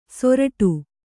♪ soraṭu